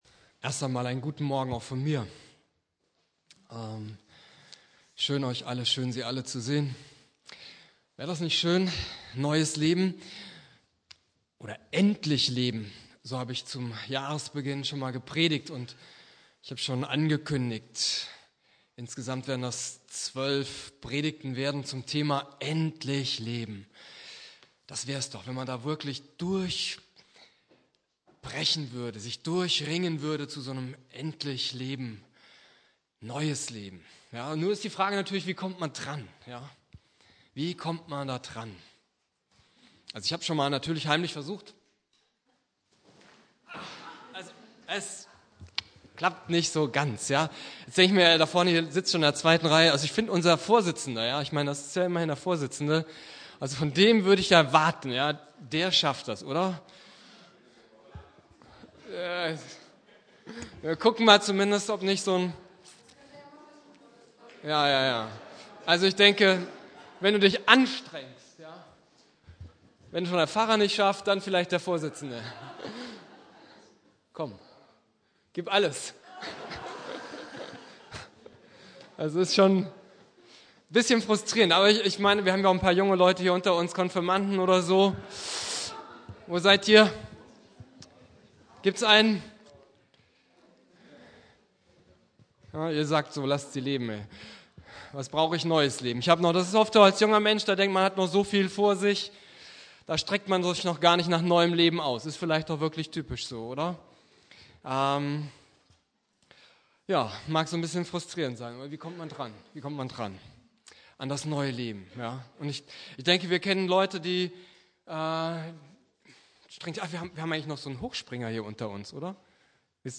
"Nie mehr allein" - Teil 2 zur Predigtreihe "Endlich leben" Inhalt der Predigt